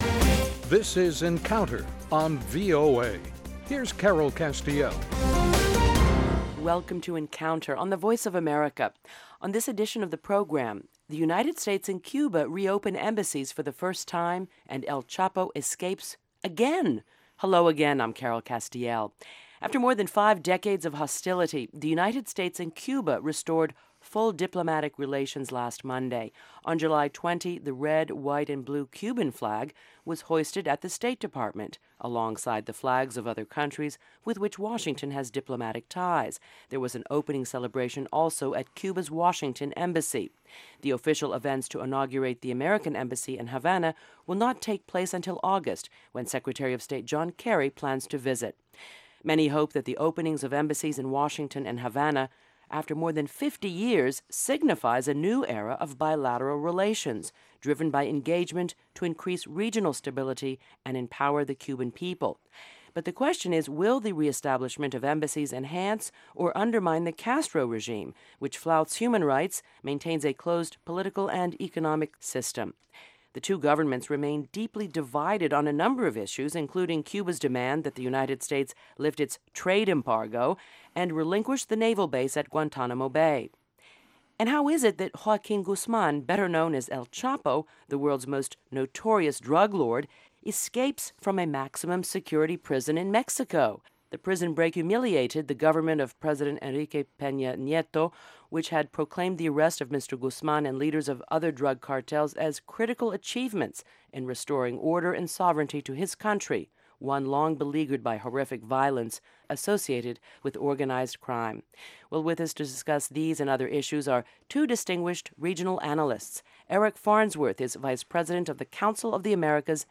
Issues that affect our lives and global stability are debated in a free-wheeling, unscripted discussion of fact and opinion.